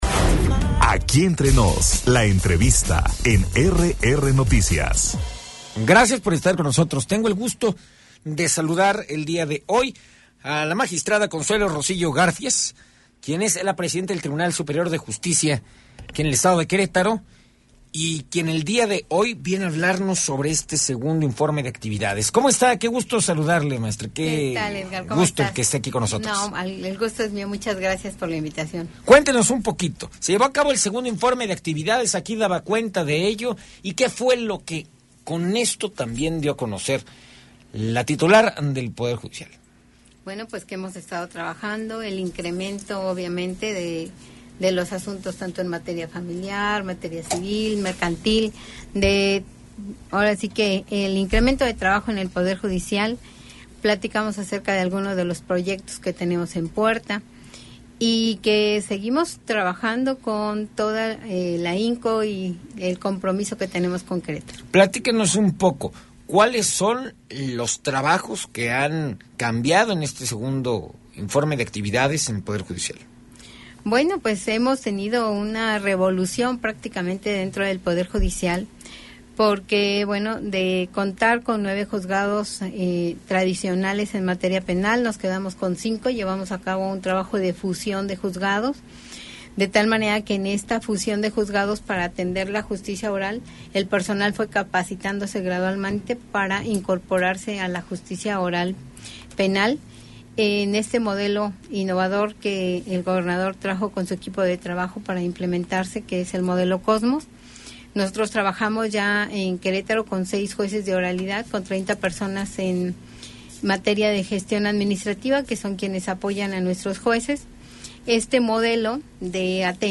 En entrevista la presidenta del Tribunal Superior de Justicia, Consuelo Rosillo